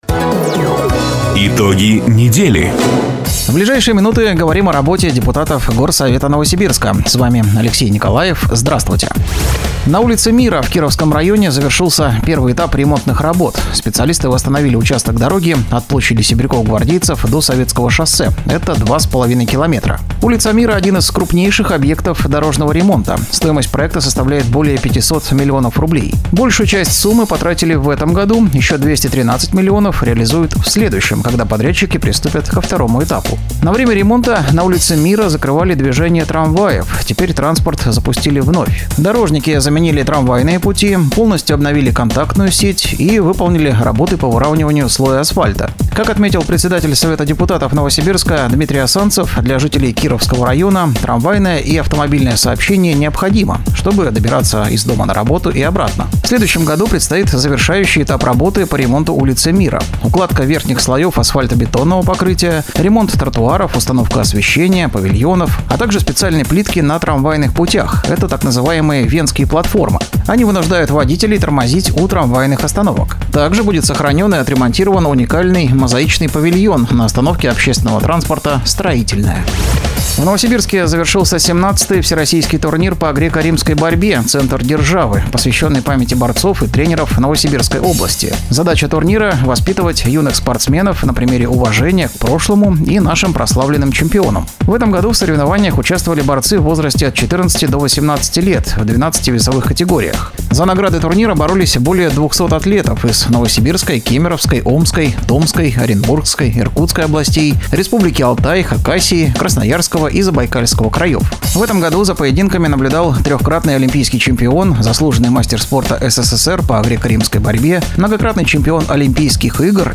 Запись программы "Итоги недели", транслированной радио "Дача" 09 ноября 2024 года.